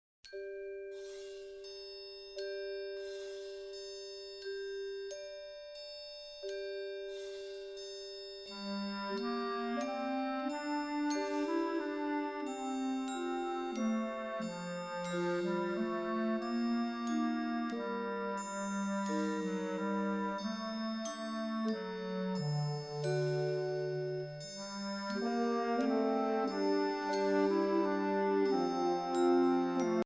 Voicing: Flute